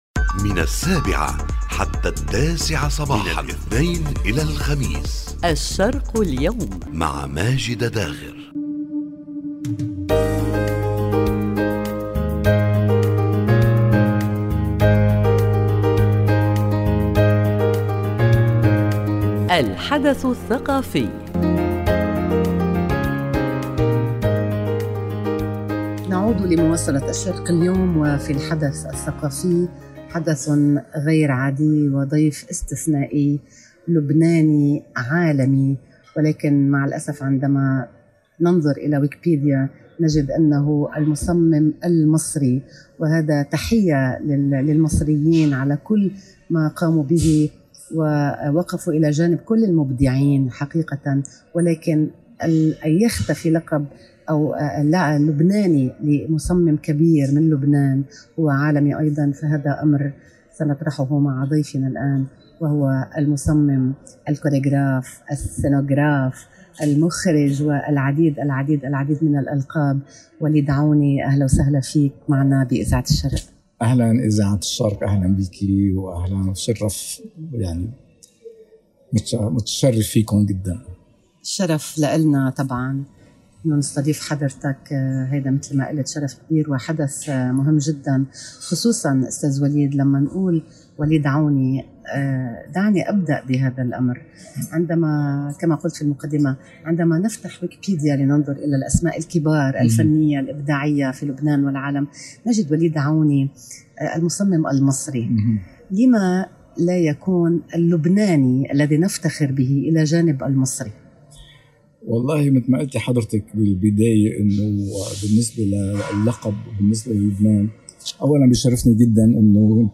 الفنان اللبناني العالمي وليد عوني ضيف
عبر إذاعة الشرق